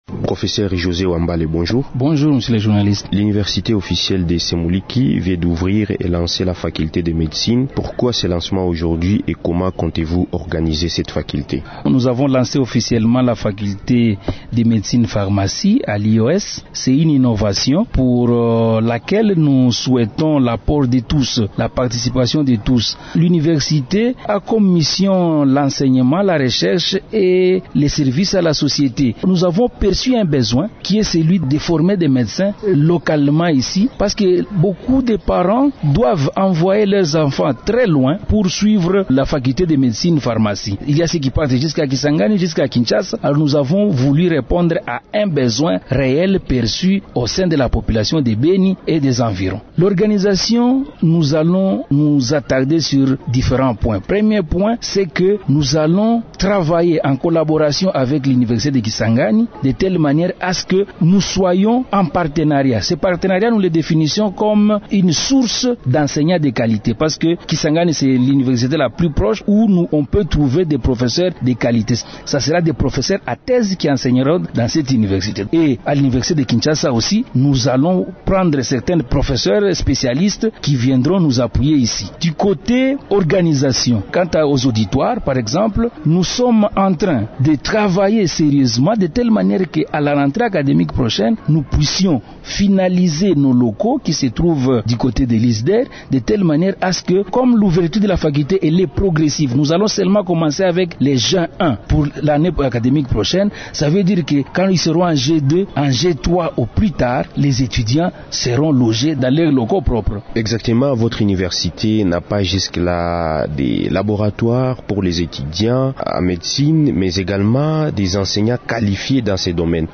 Il s’entretient